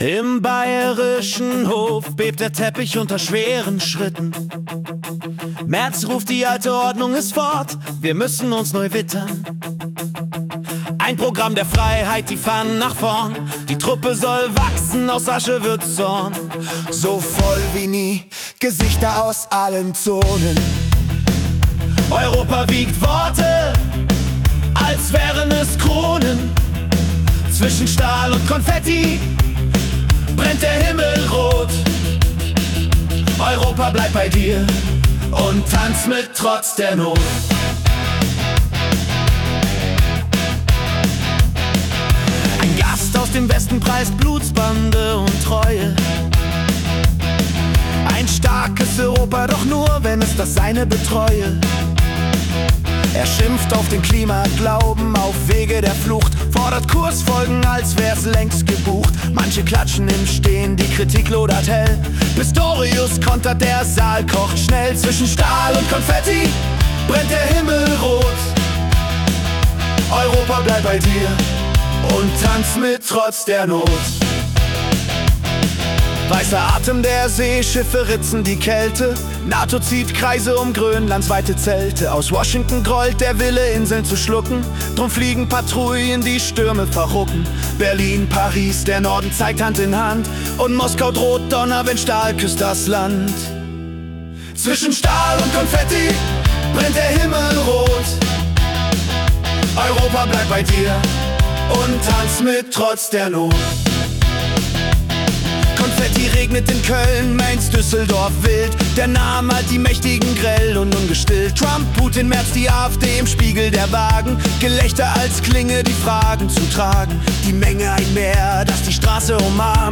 Die Nachrichten vom 17. Februar 2026 als Rock-Song interpretiert.
Jede Folge verwandelt die letzten 24 Stunden weltweiter Ereignisse in eine mitreißende Rock-Hymne. Erlebe die Geschichten der Welt mit fetzigen Riffs und kraftvollen Texten, die Journalismus...